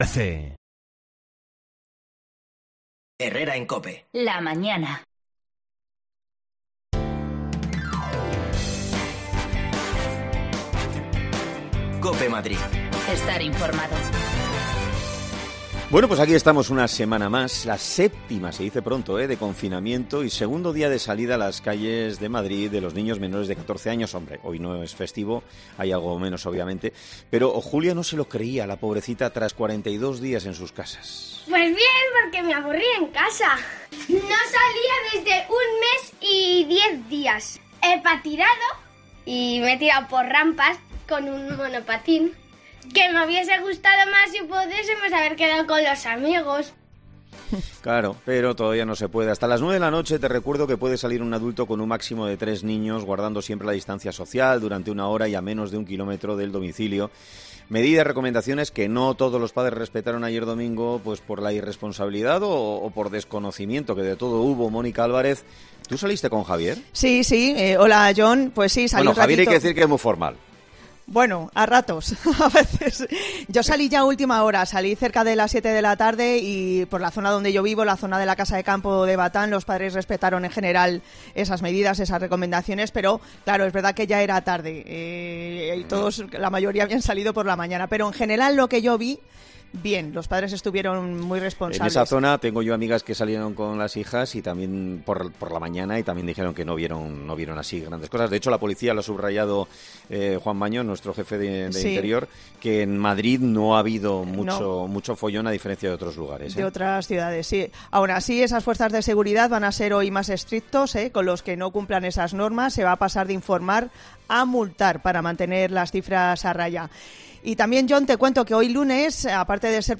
AUDIO: Te actualizamos las cifras del coronavirus en Madrid y escucharemos testimonios de madrileños que han pasado a formar parte de un ERTE
Las desconexiones locales de Madrid son espacios de 10 minutos de duración que se emiten en COPE , de lunes a viernes.